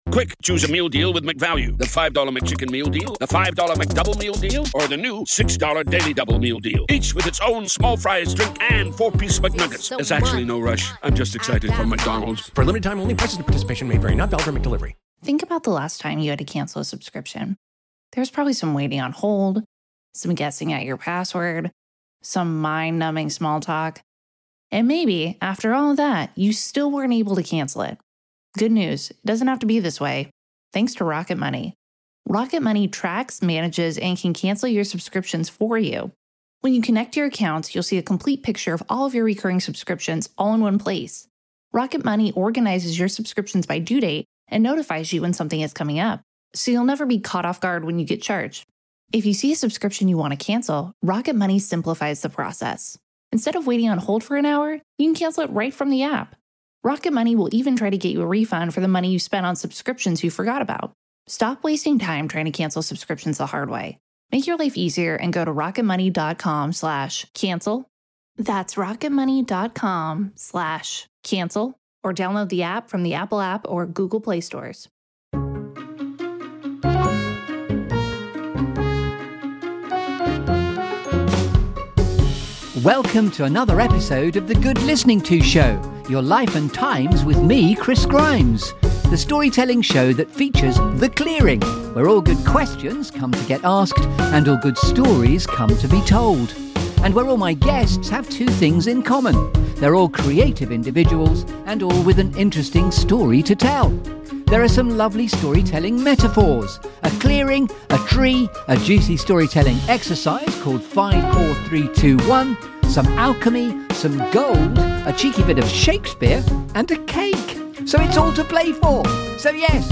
This feel-good Storytelling Show that brings you ‘The Clearing’.